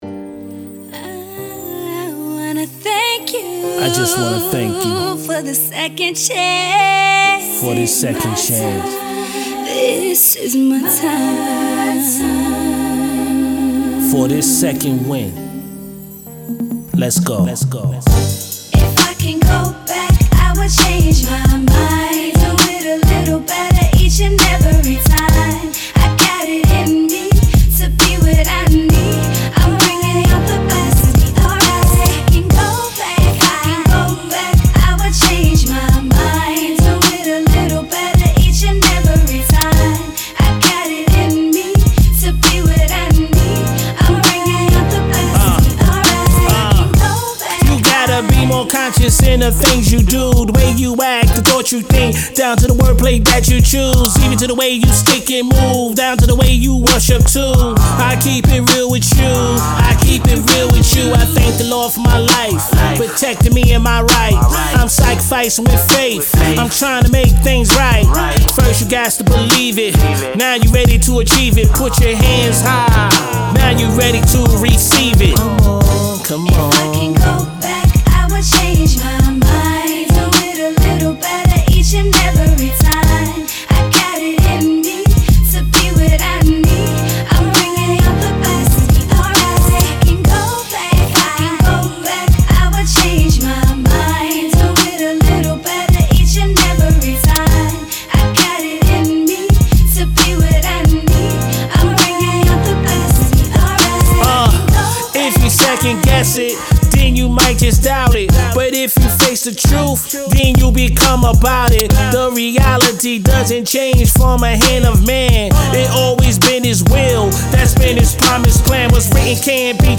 🎶 Soulful vibes, hard work, and pure passion in the studio!